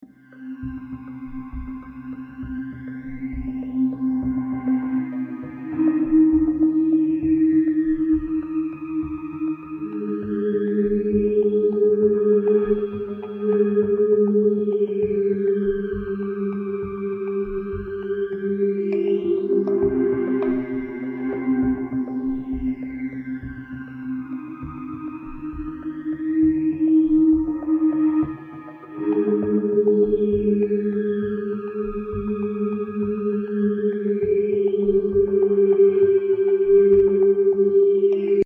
环境现实垫子1
Tag: 100 bpm Ambient Loops Pad Loops 6.47 MB wav Key : C